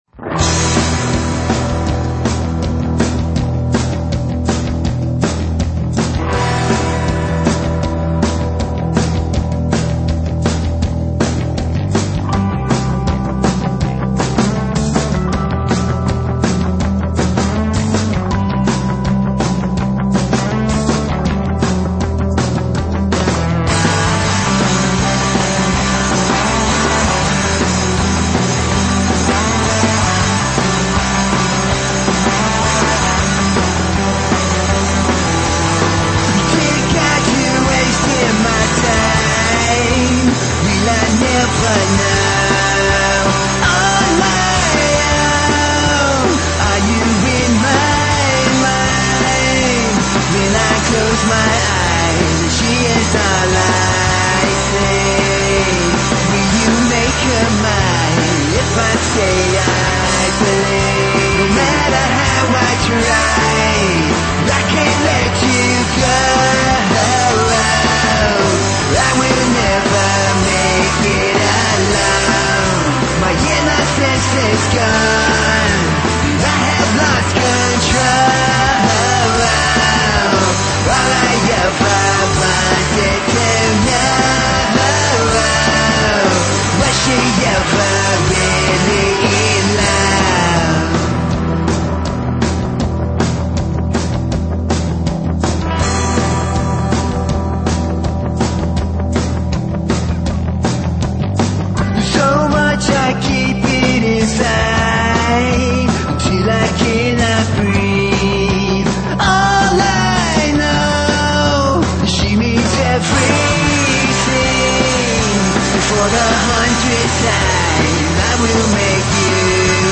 punk
metal
high energy rock and roll